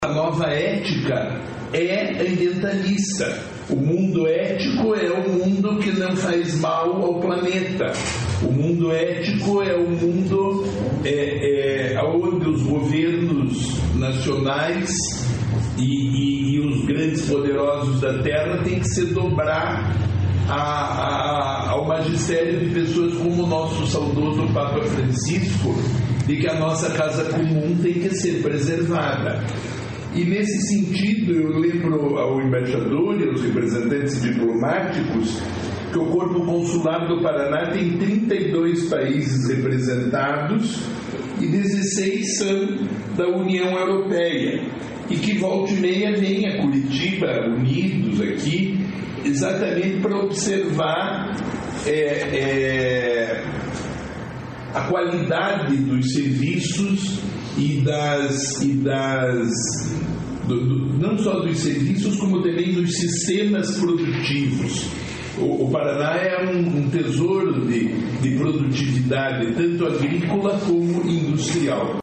Sonora do secretário do Desenvolvimento Sustentável, Rafael Greca, sobre apresentação de ações de sustentabilidade a embaixador do Brasil na União Europeia